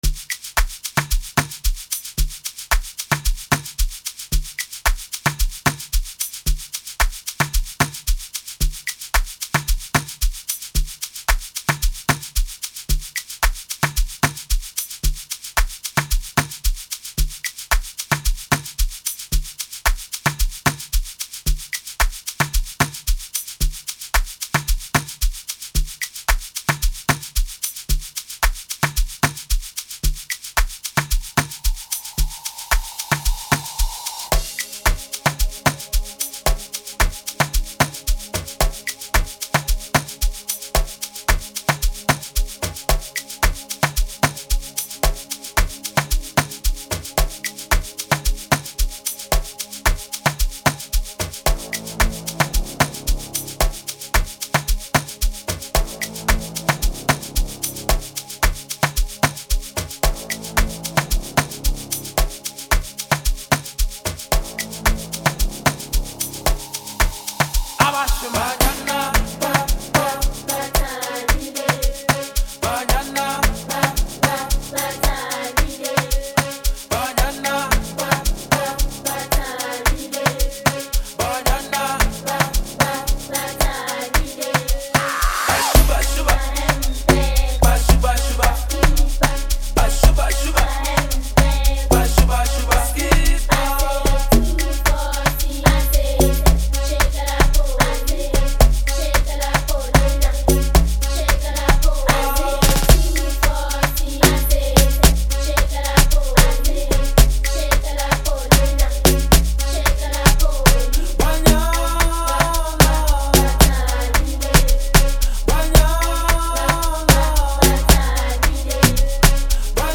Amapiano genre